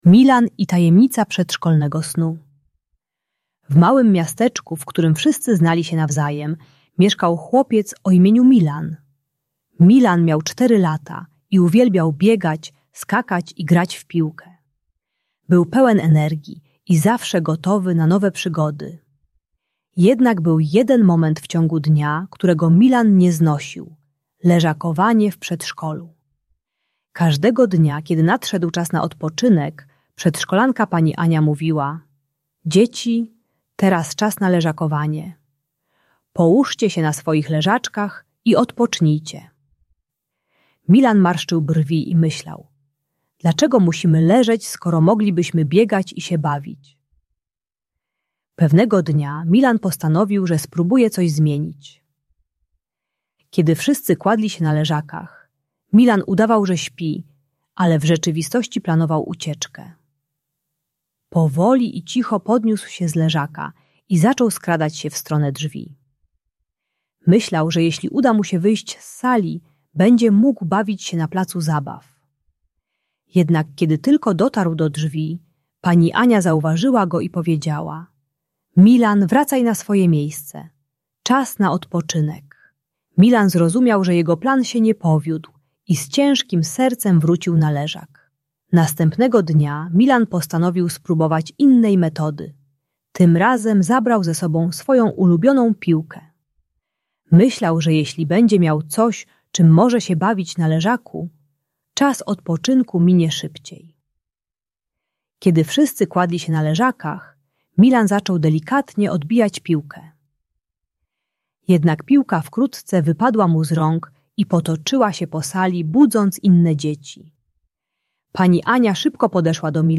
Tajemnica snu Milana - Przedszkole | Audiobajka